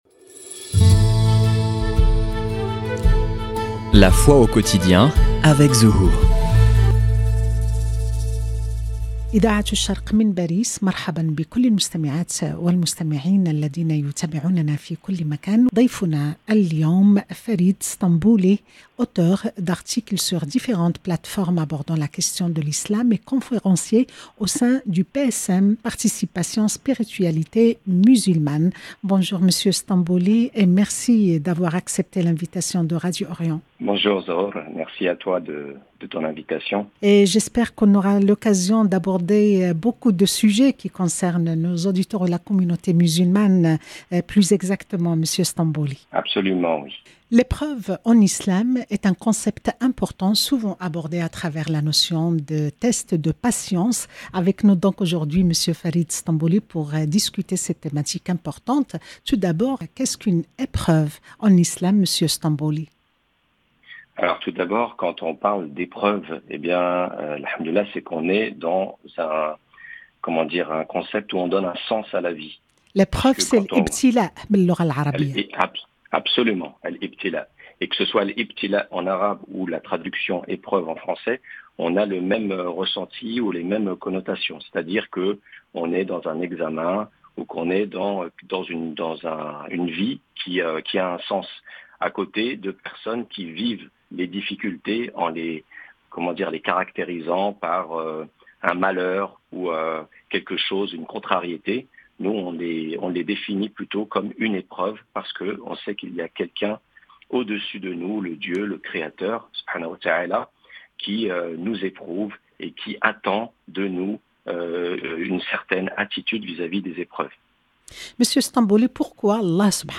Dans cette intervention exclusive